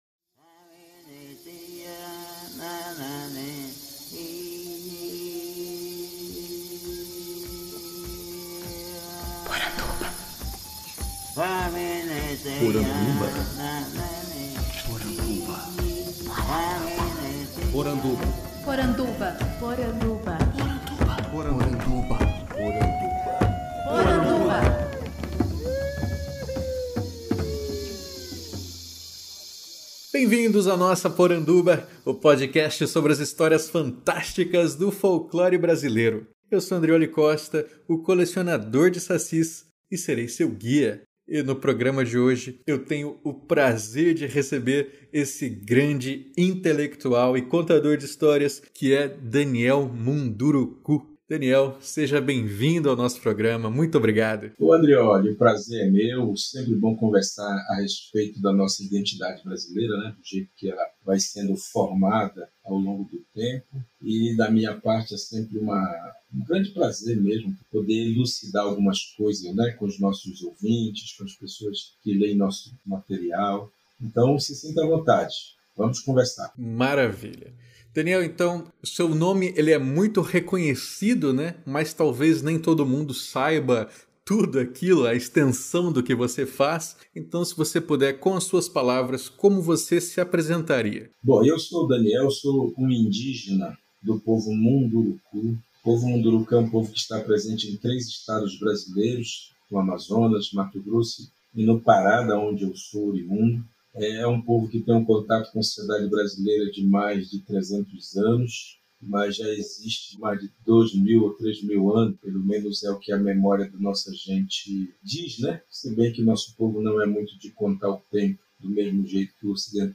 Podcast que entrevista o filósofo, intelectual e contador de histórias.